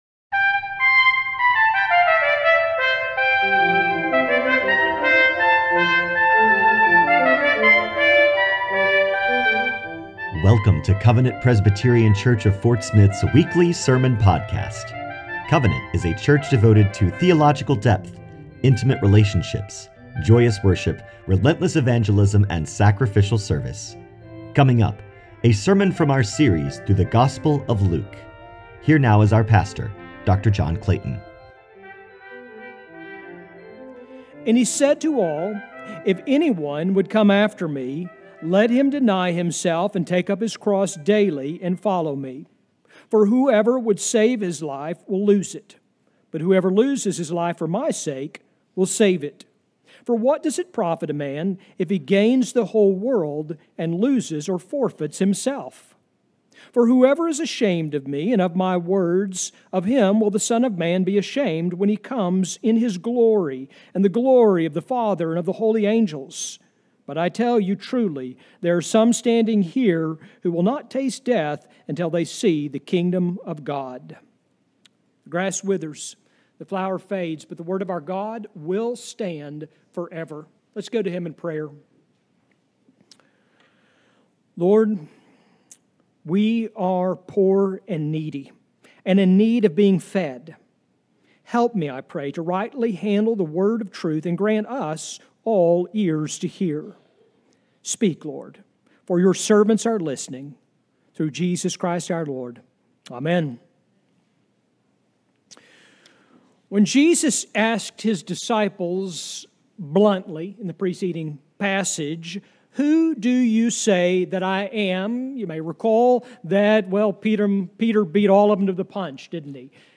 The weekly sermons from Covenant Presbyterian Church of Fort Smith.
Sermons from Covenant Presbyterian Church of Fort Smith (PCA)